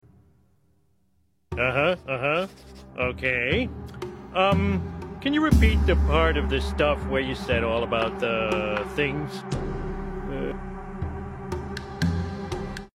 Narrated with care....ignored with confidence.